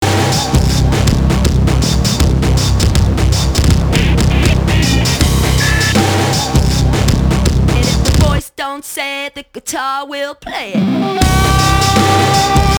the sound of the female voice that cuts in to the song